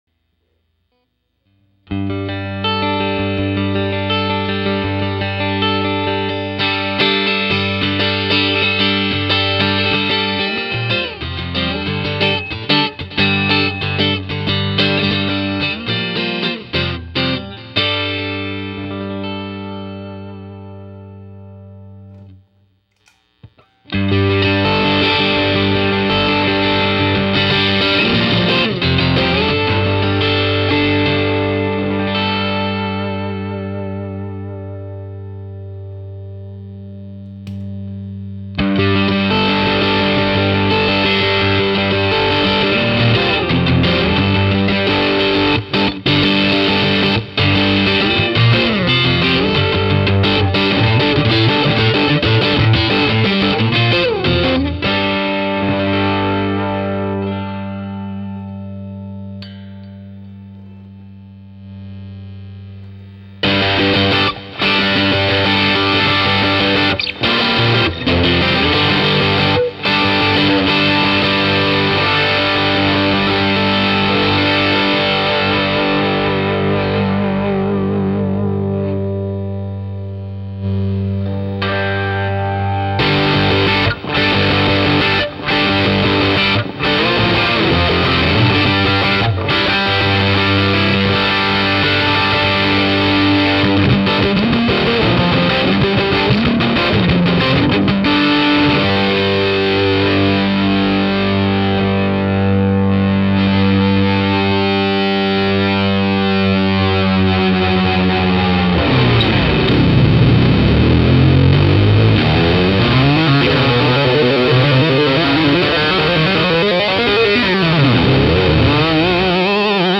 These recordings are not of high quality, so keep in mind that its not like experiencing the pedal for yourself.
Notice that the amp is totally clean and quiet, volume set below 4.
What you are hearing is not the amp but the Zachary Pedal.
1. Clean amp only - pedal turned off,
2. Drive 12 o'clock, Boost off (starter setting) - neck pup single coil
5. Drive 12 o'clock, Boost maximum (25db) - bridge pup double coil
6. Drive maximum, Boost maximum (25db) - bridge pup double coil
Sounds quite cool as well, those sort of comical explosion sounds.
It sounds like something is going to explode.
And feeds back like crazy even with the amp not hugely loud.